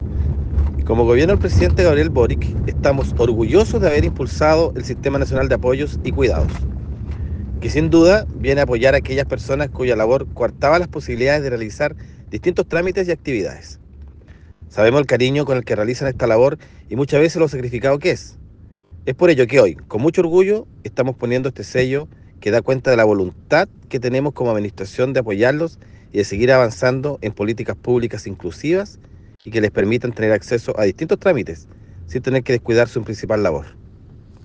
CUÑA DPR